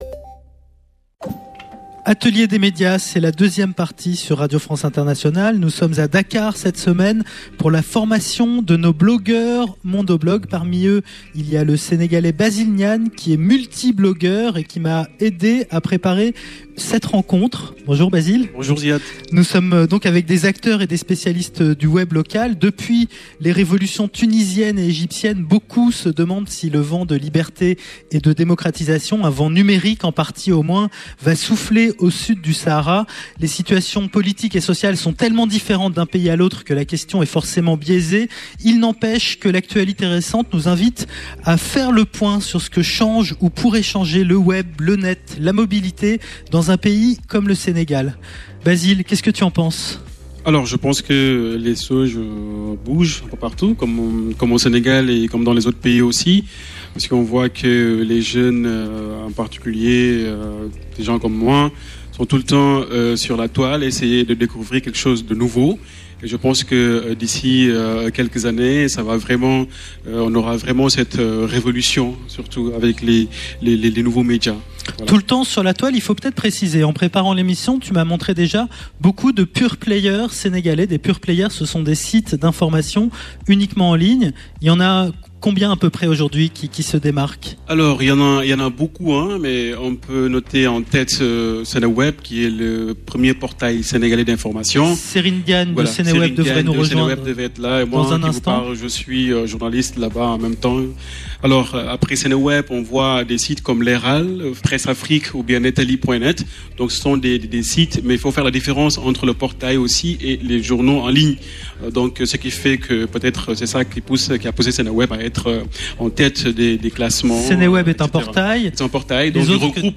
Emission enregistrée à l’Institut Français de Dakar.